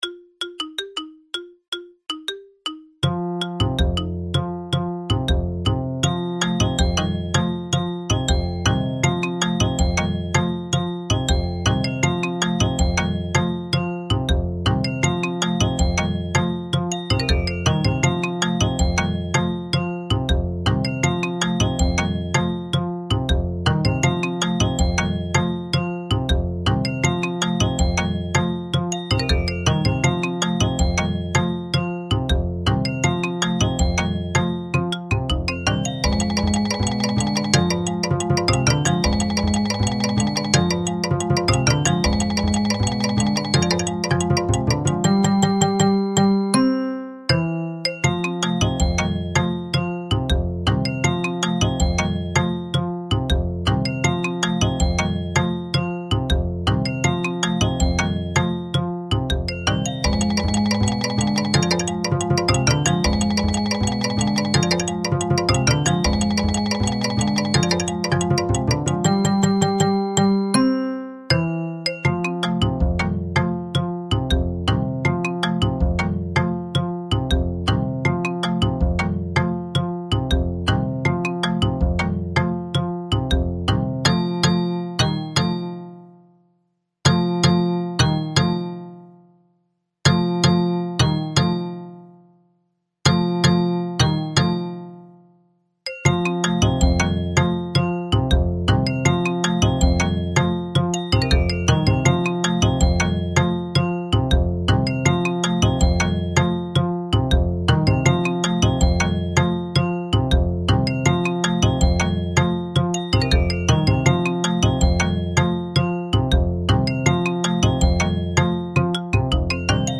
Rubriek: Mallet-Steelband
Bells Xylofoon Marimba Bass Percussion Drums